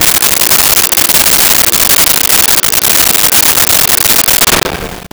Lofi Drum Roll 01
LoFi Drum Roll 01.wav